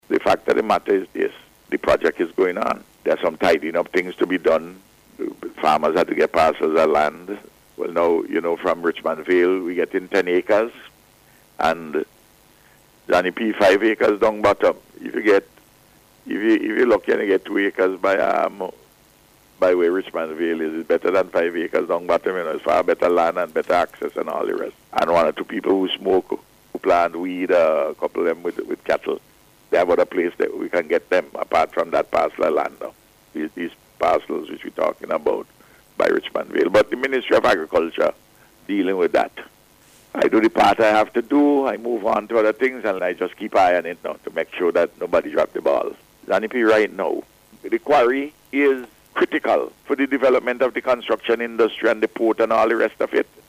Providing an update on the project, Prime Minister Dr. Ralph Gonsalves said the quarry is critical for the development of the construction industry here.